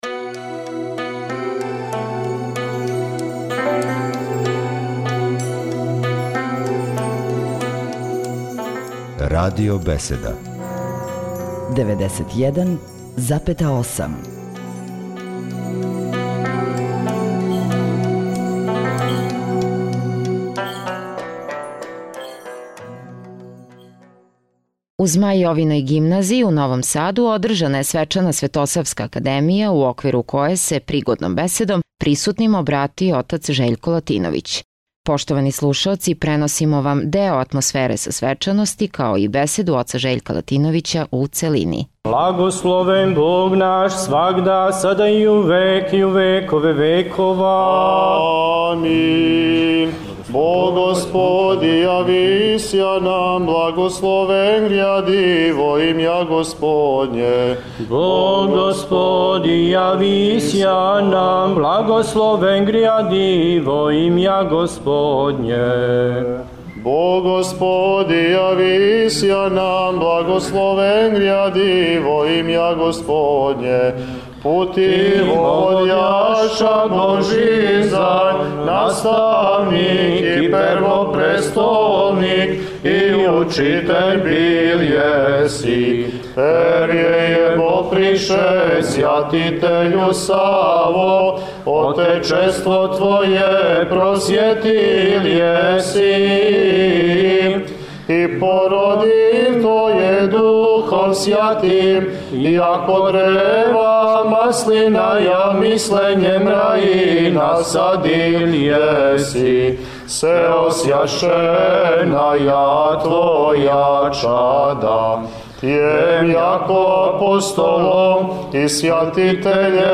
Нови Сад – У Саборном храму Светог Великомученика Георгија у Новом Саду, на празник Светога Саве, првог Архиепископа и просветитеља српског, свету архијерејску Литургију служио је Епископ јегарски Господин др Порфирије.